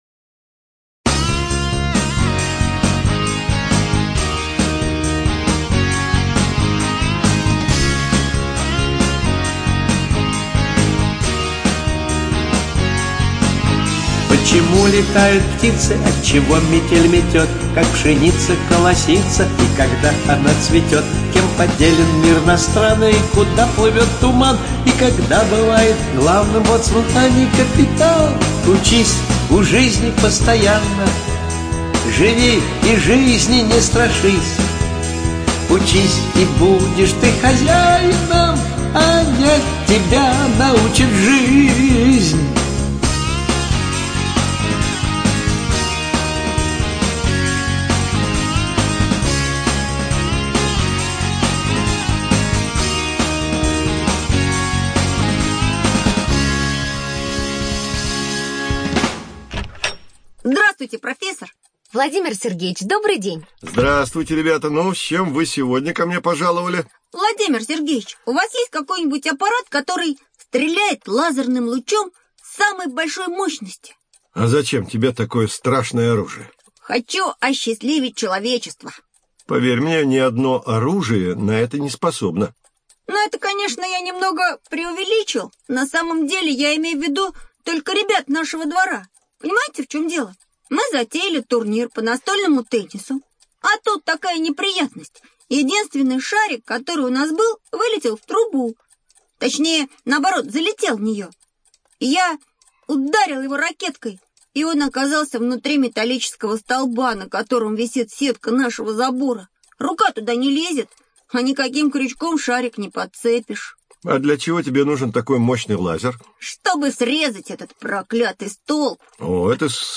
АвторРазвивающая аудиоэнциклопедия
Студия звукозаписиАрдис